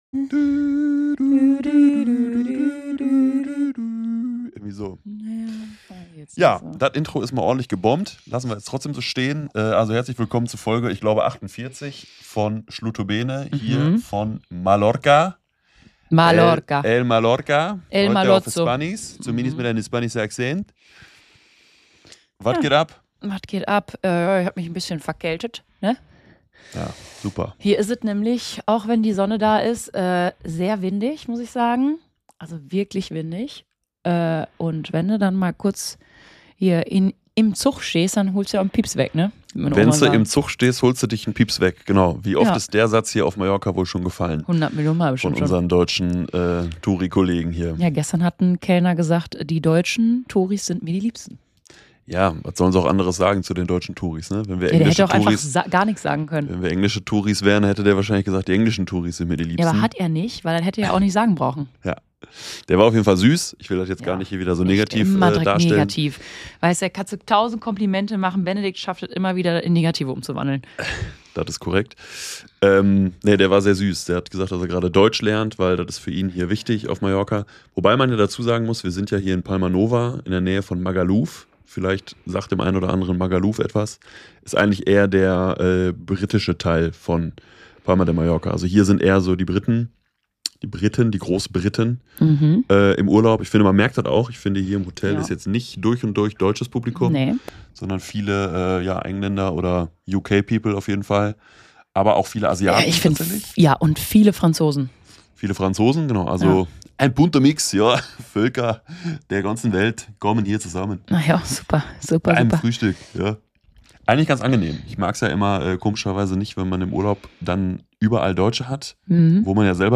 Eine neue Folge SCHLUTTO-BENE aus dem White Lotus Hotel Mallorca.